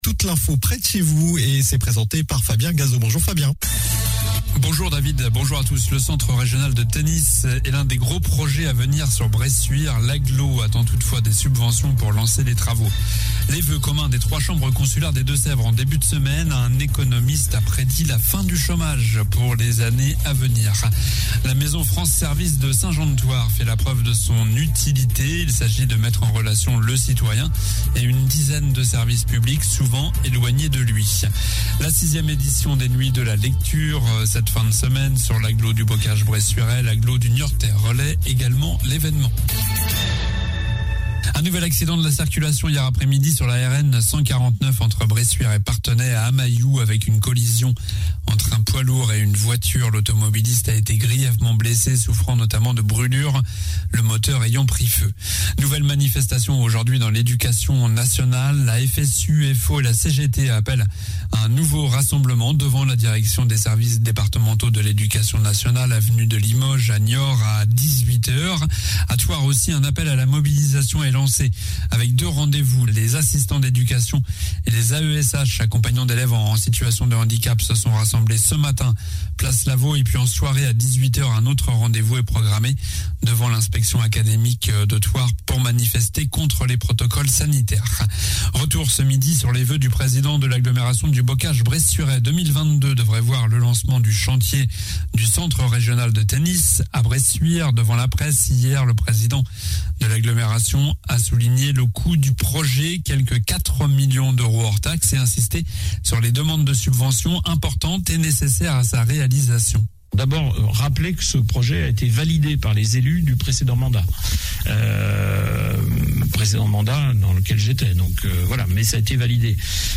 Journal du jeudi 20 janvier (midi)